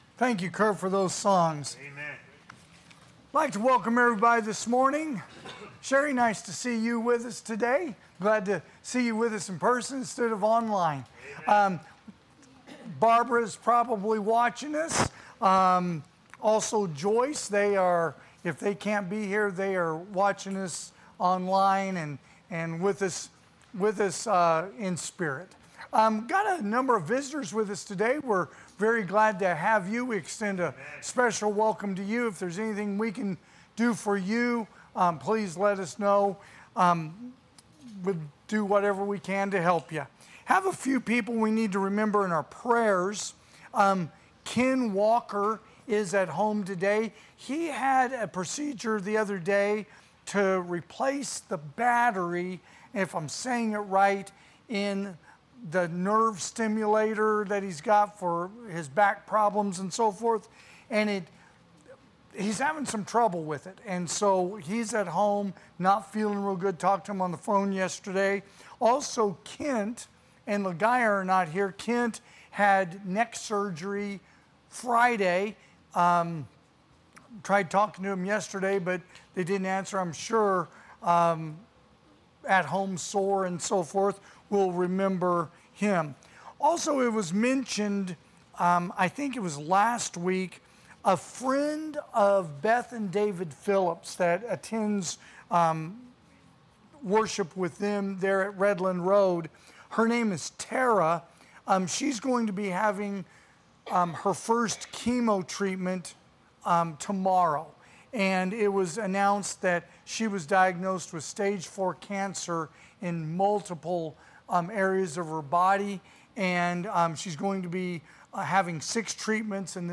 2024 (AM Worship)
Sermons